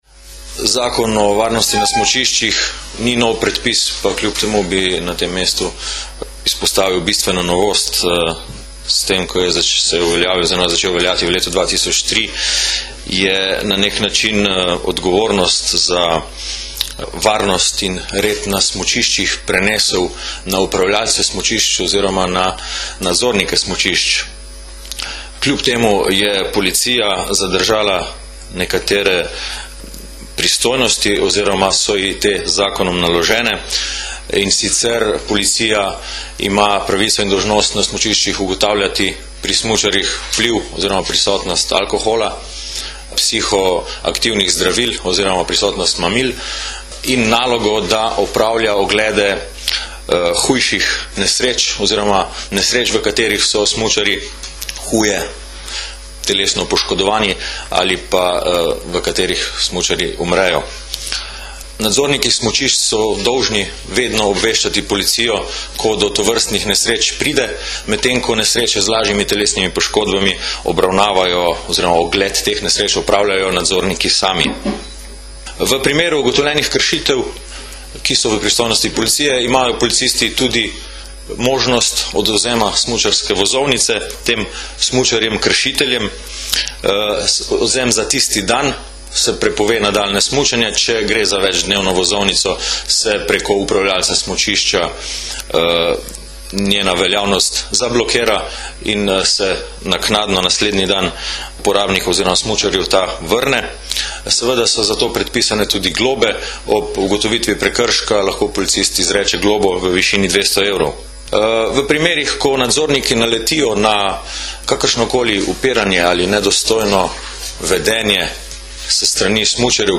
Ministrstvo za promet je danes, 3. decembra 2010, organiziralo medresorsko novinarsko konferenco pred začetkom smučarske sezone, kjer je svojo vlogo predstavila tudi policija.
Zvočni posnetek izjave